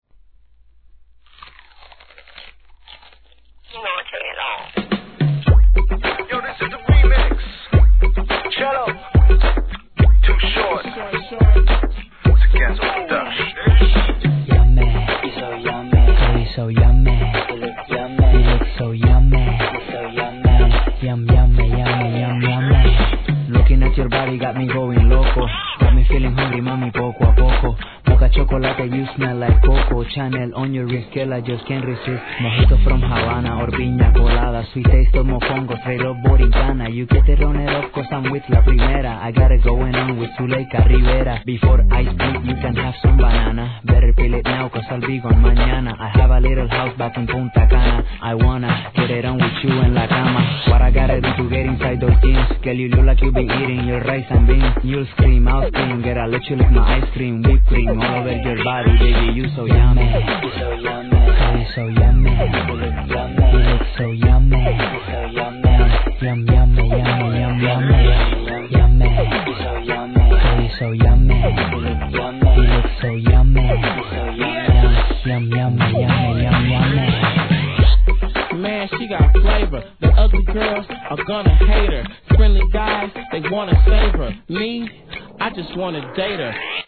HIP HOP/R&B
単調で軽い打楽器のポコポコ感とハンドクラップがジワジワ系!